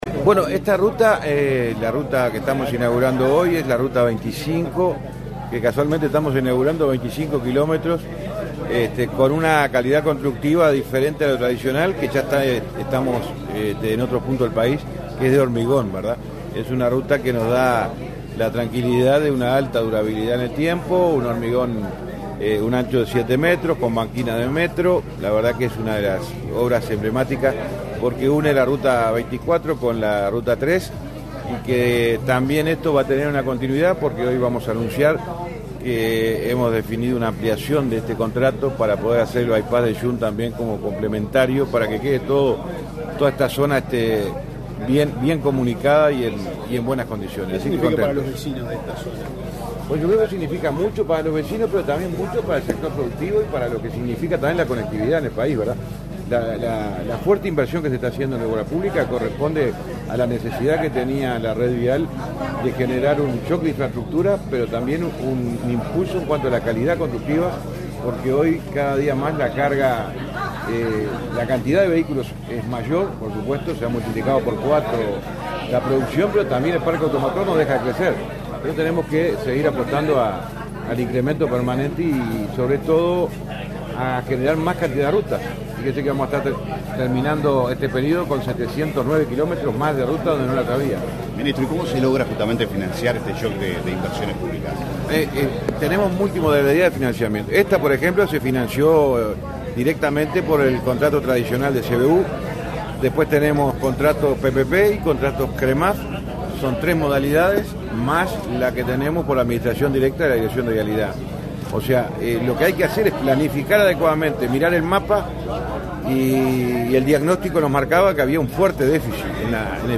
Declaraciones a la prensa del titular del MTOP, José Luis Falero